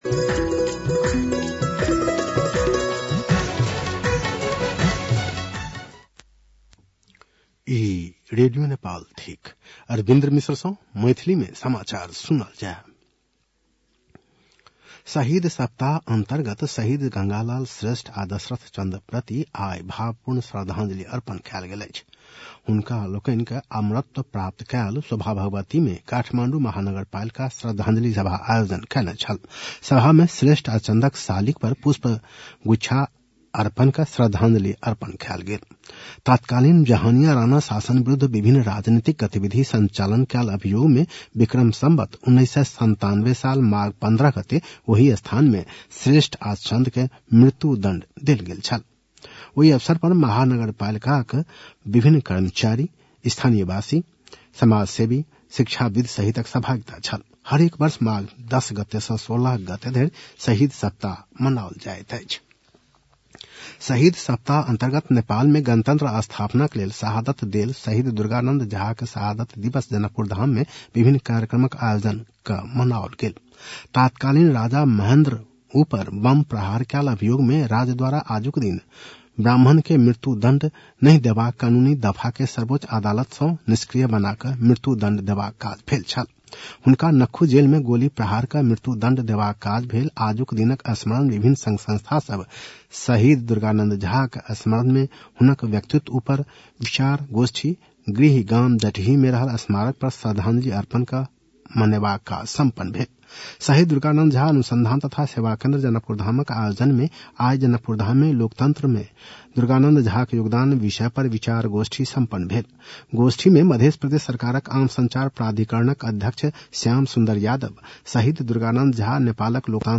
मैथिली भाषामा समाचार : १६ माघ , २०८१